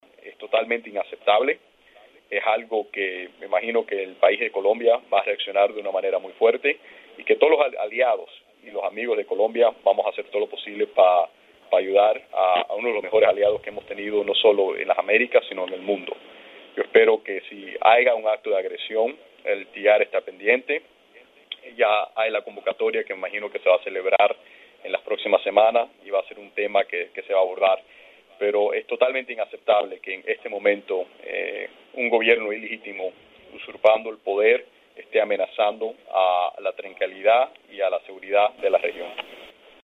Dos minutos antes de la hora fijada para una conferencia de prensa telefónica de Trujillo sobre la convocatoria de 11 países del hemisferio para aplicar el TIAR en Venezuela (en el marco de la OEA), el presidente Donald Trump anunció en un mensaje de Twitter el despido del asesor de Seguridad Nacional John Bolton, uno de los artífices de la política de la Casa Blanca hacia el régimen de Maduro.